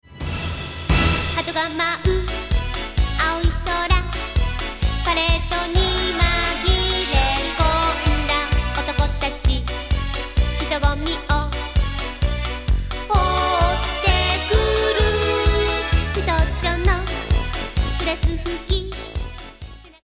ベースとギターの演奏を掛け算
下の試聴サンプルの１が、基になったベースとギターのMIDIデータです。どんな演奏か、TTS-1というソフトウェアシンセサイザで鳴らしてみました。
EMM-Knagalisというソフトウェアシンセサイザで鳴らしてみました。
» １．基になったベースとギターの演奏 » ２．かけ算した結果のウードの演奏